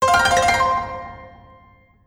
se_pause.wav